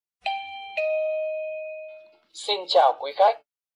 Âm thanh Xin Chào Quý Khách
Description: Tải âm thanh “Xin Chào Quý Khách” giọng nam mp3, còn gọi là file chào khách, lời chào tự động, thông báo chào mừng, được dùng phổ biến trong cửa hàng, siêu thị, khách sạn, bệnh viện, nhà hàng, hệ thống loa cảnh báo, chống trộm. File mp3 giọng nam rõ ràng, dễ nghe, có thể phát qua loa tự động, cảm biến cửa hoặc hệ thống âm thanh thông minh.
am-thanh-xin-chao-quy-khach-www_tiengdong_com.mp3